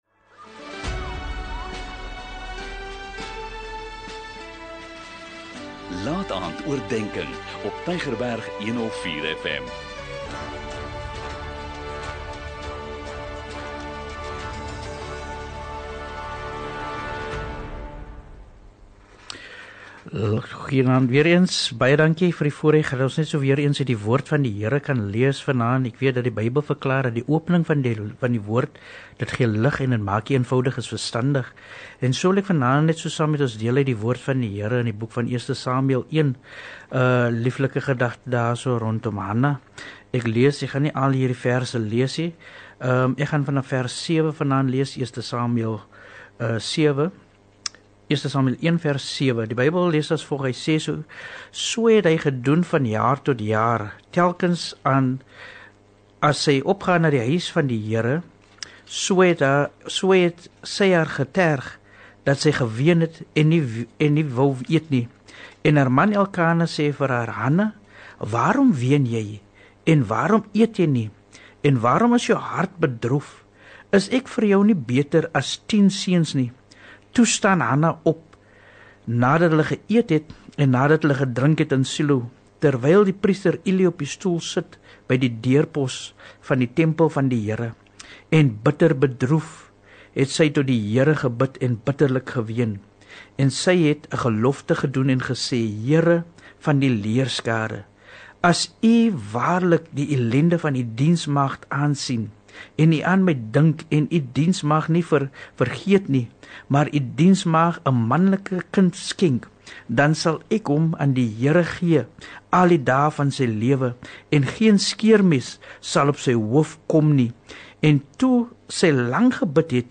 'n Kort bemoedigende boodskap, elke Sondagaand om 20:45, aangebied deur verskeie predikers.